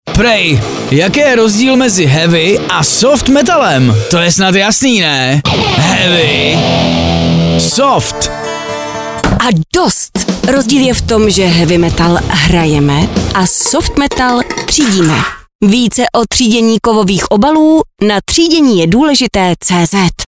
Rádio spot Metal